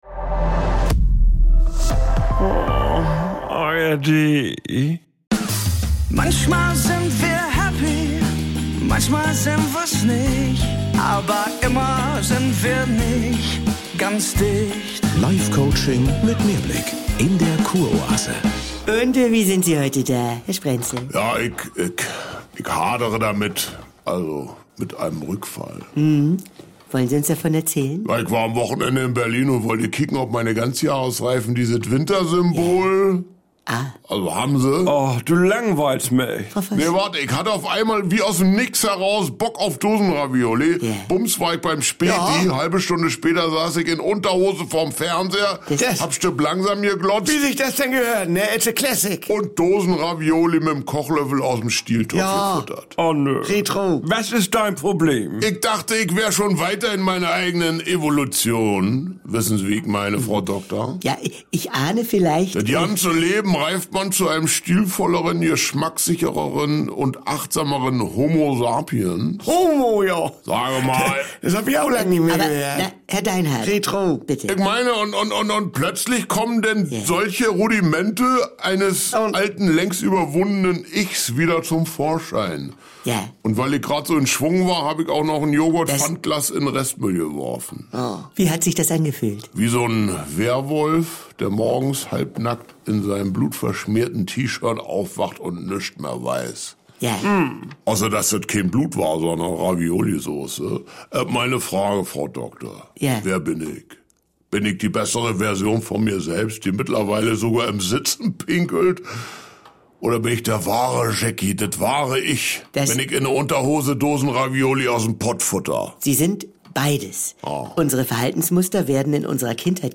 … continue reading 262 פרקים # Komödie # NDR